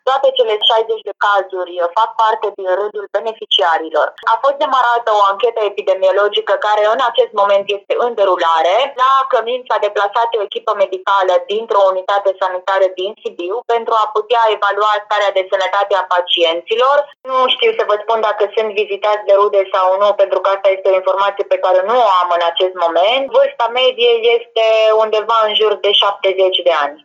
Direcția de Sănătate Publică a început o anchetă pentru a afla cum s-au îmbolnăvit bătrânii, a spus la Europa FM